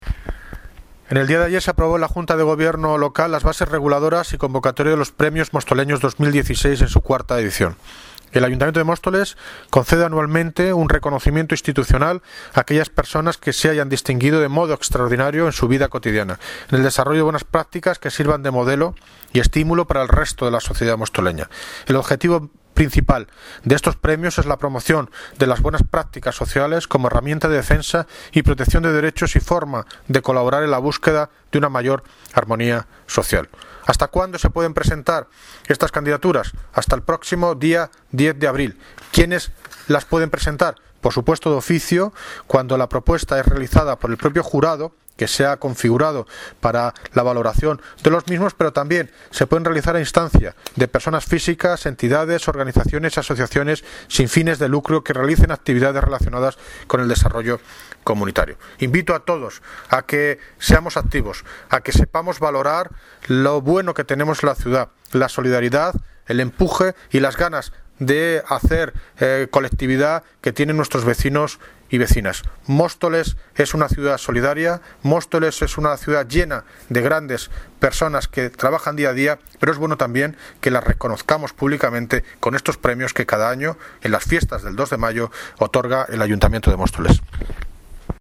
El alcalde de Móstoles anima a la ciudadanía a participar en la IV Edición de los Premios Mostoleños 2016
Audio - David Lucas (Alcalde de Móstoles) Sobre Premios Mostoleños 2016